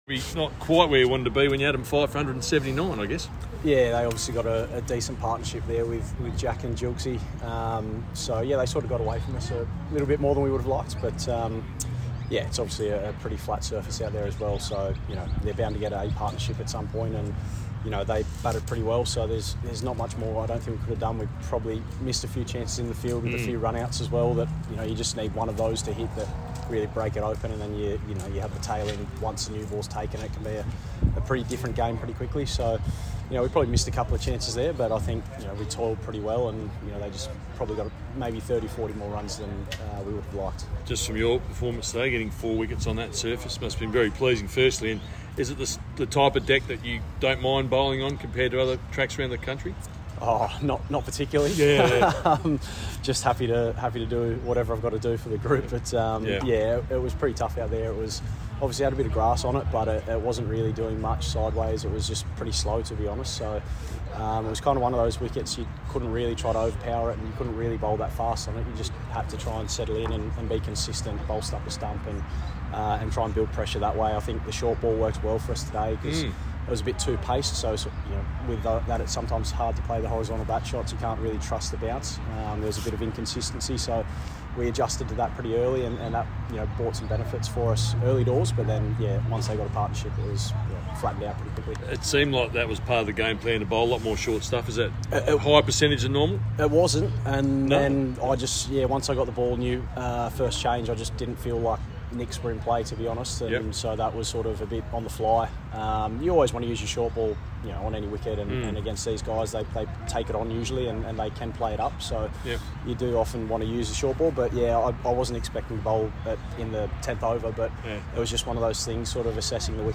speaking at stumps in Adelaide against NSW